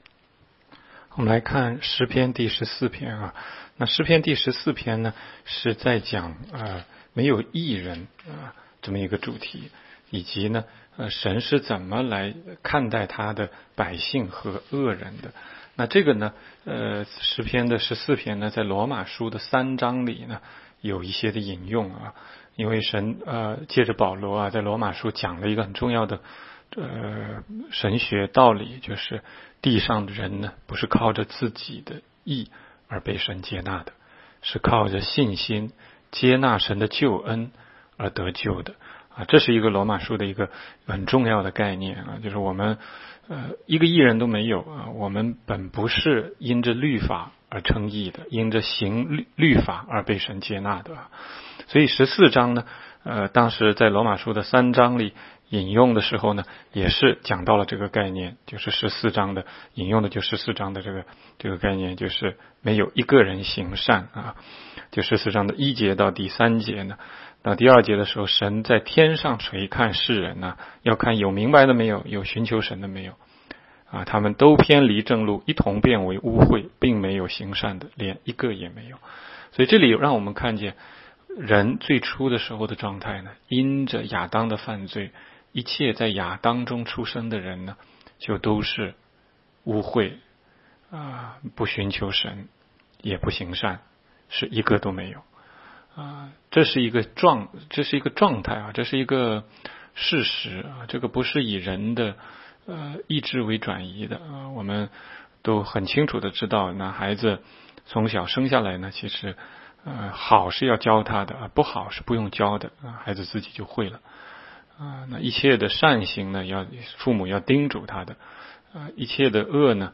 16街讲道录音 - 每日读经-《诗篇》14章